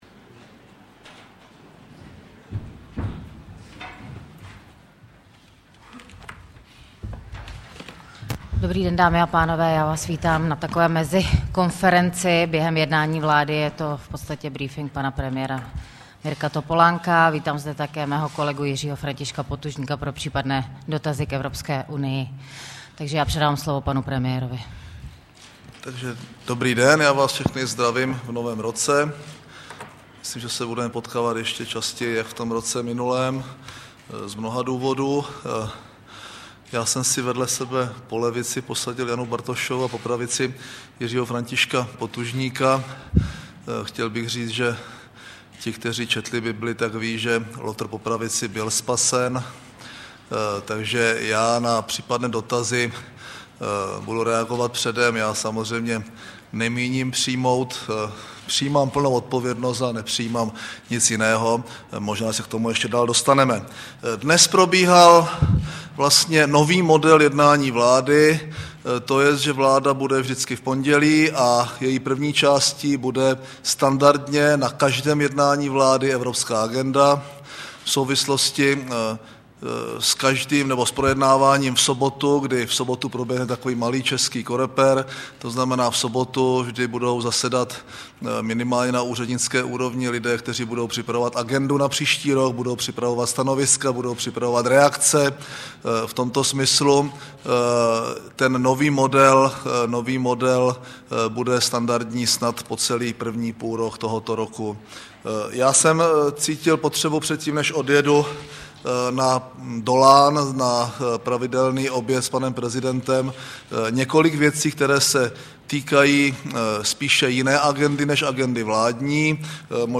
Zpráva nabízí zvukový záznam tiskové konference po jednání vlády a informace o návrzích zákonů, které v pondělí 5. ledna schválil kabinet premiéra Mirka Topolánka.